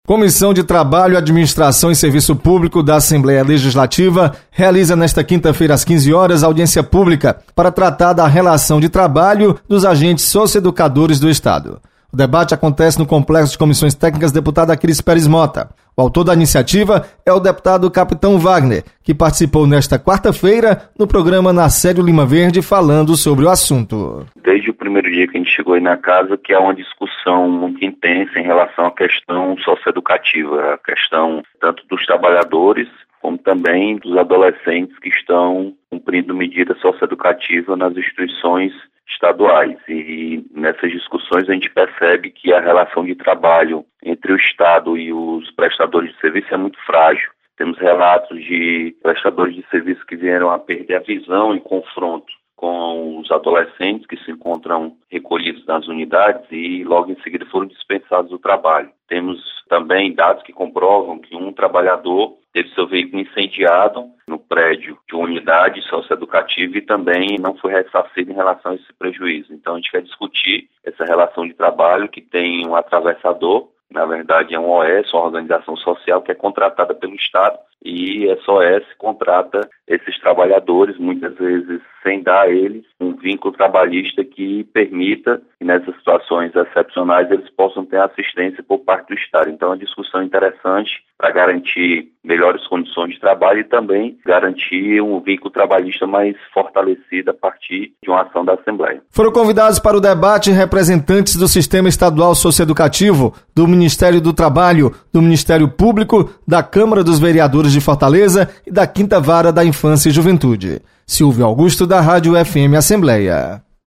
Deputado Capitão Wagner participa de audiência pública para debater relação de trabalho dos agentes socioeducadores. Repórter